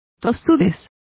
Complete with pronunciation of the translation of stubbornness.